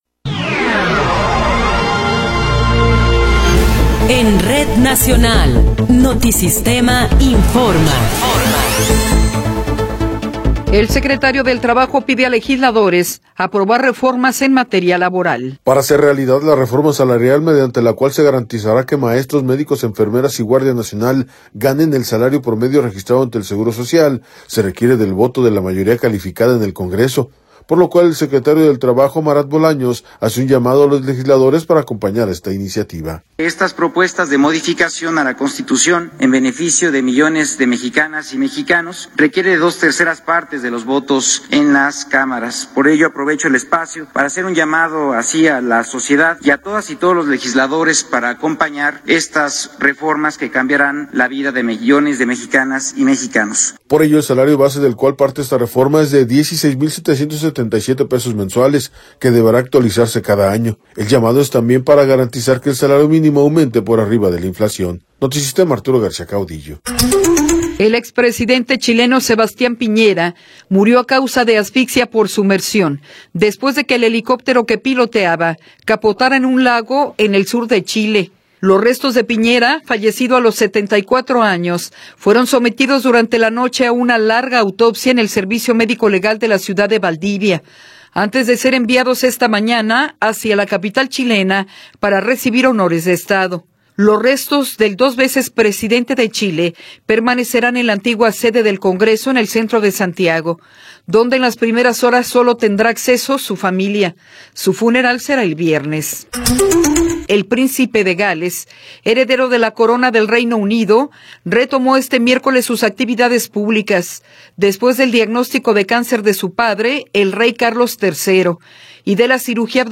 Noticiero 10 hrs. – 7 de Febrero de 2024